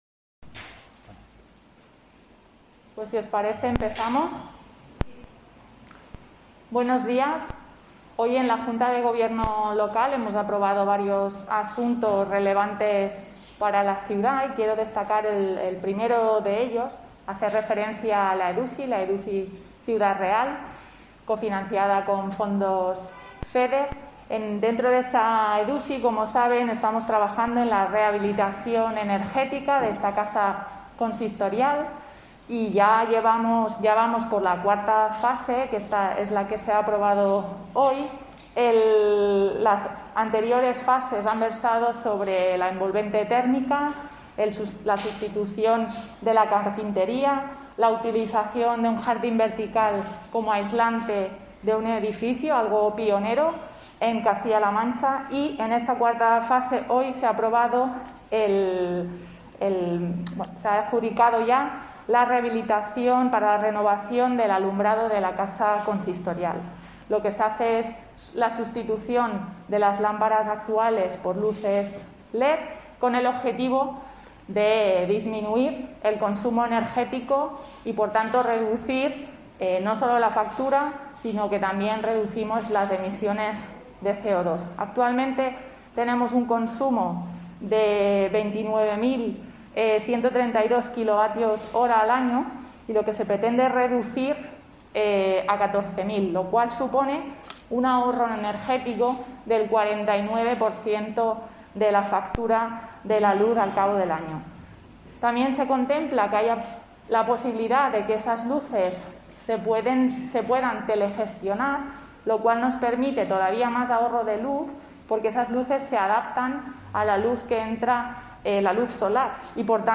Mariana Boadella, portavoz del Gobierno de Ciudad Real